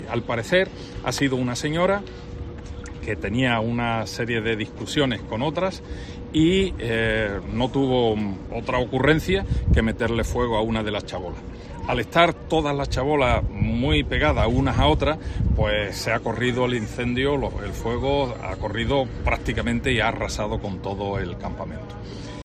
Carmelo Romero, alcalde de palos de la Frontera